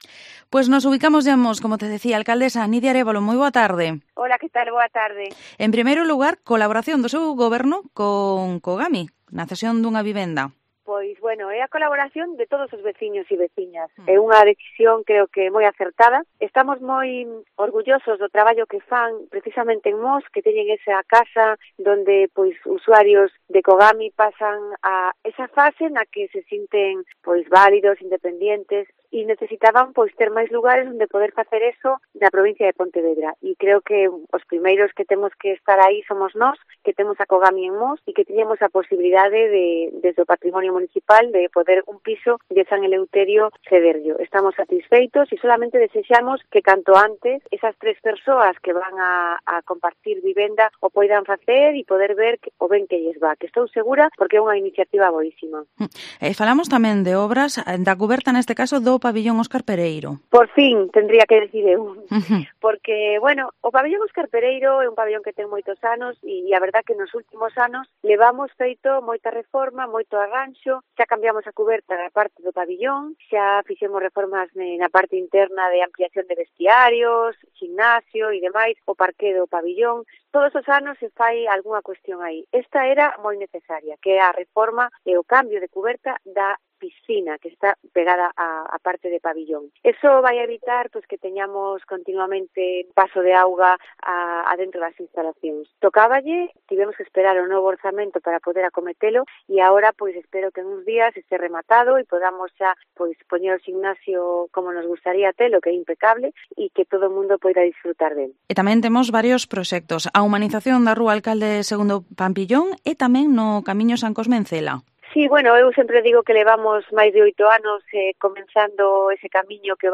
Entrevista a la Alcaldesa de Mos, Nidia Arévalo